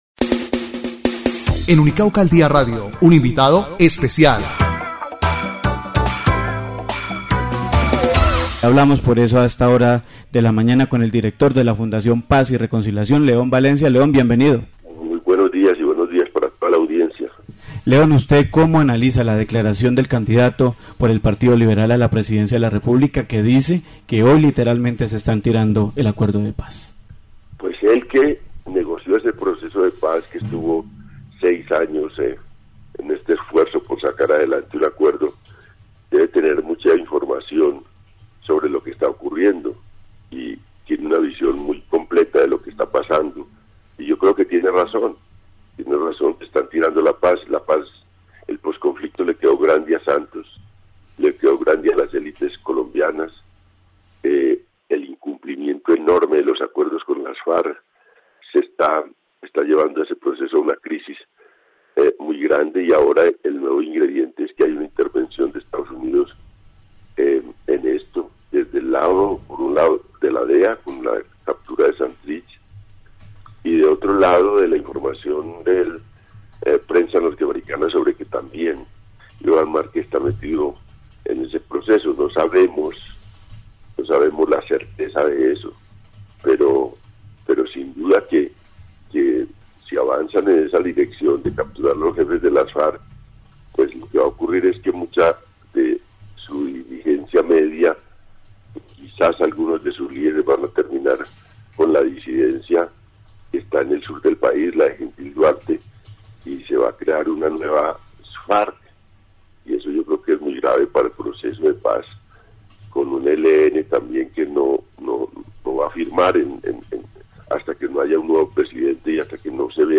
El informativo Unicauca al Día Radio realizó la entrevista al Analista León Valencia
Audio entrevista León Valencia